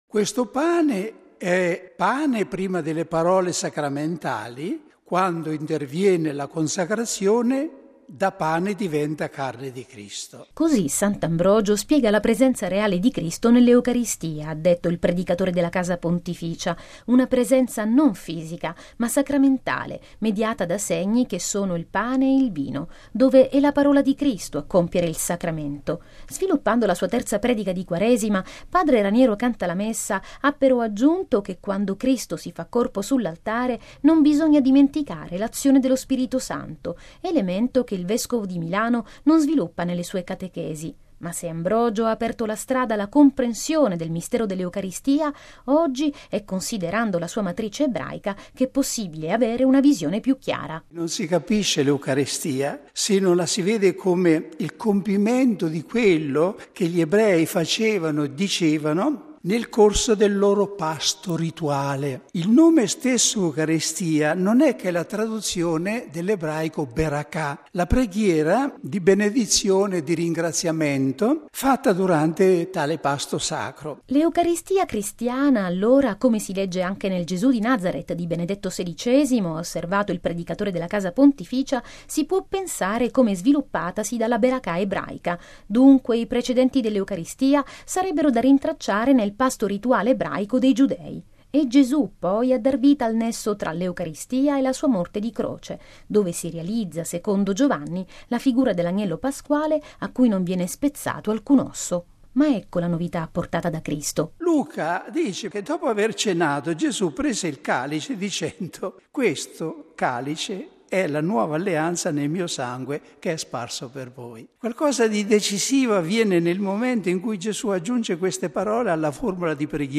◊   Per spiegare l’Eucaristia, questa mattina, padre Raniero Cantalamessa, nella terza predica di Quaresima, tenuta alla presenza di Papa Francesco, nella Cappella Redemptoris Mater del Palazzo Apostolico, in Vaticano, si è servito degli insegnamenti di Sant’Ambrogio, ma ha raccomandato come nuova risorsa il riavvicinamento tra cristiani ed ebrei. Conoscere la liturgia ebraica, ha detto il predicatore della Casa Pontificia, aiuta a comprendere meglio i gesti di Cristo.